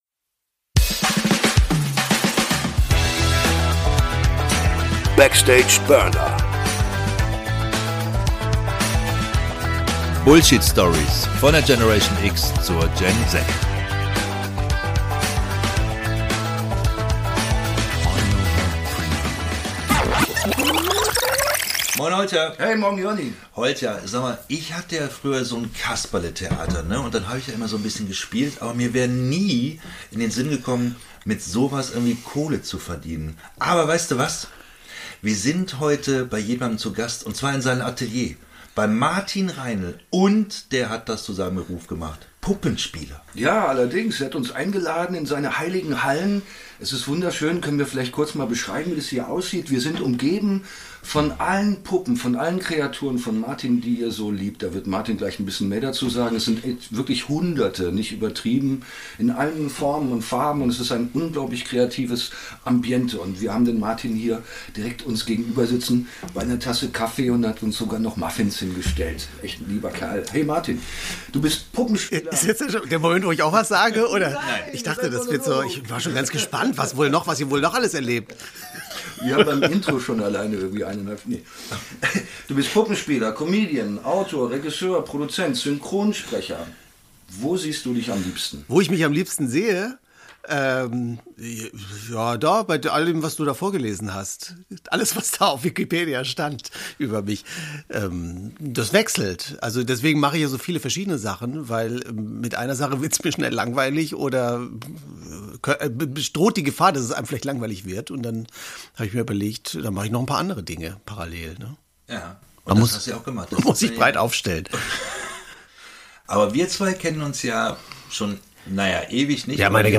im Gespräch ~ Backstage Burner Podcast